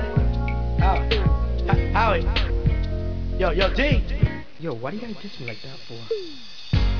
AJ arguing with Howie (76kb)